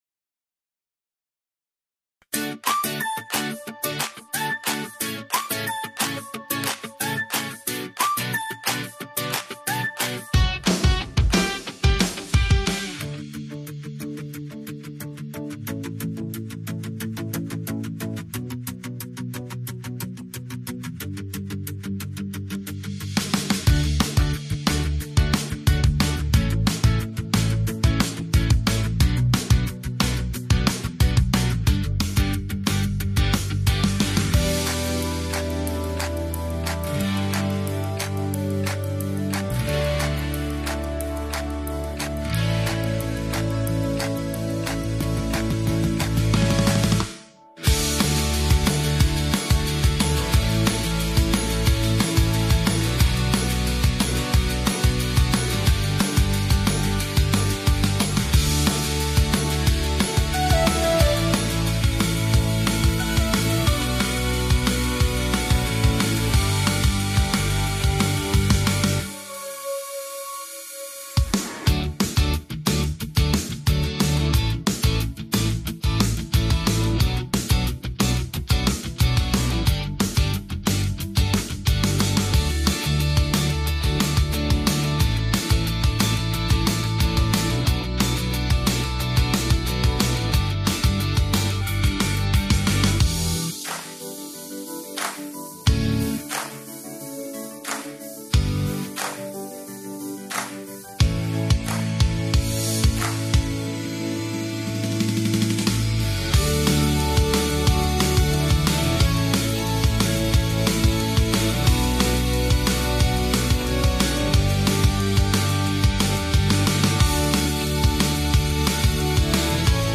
File audio BASE della canzone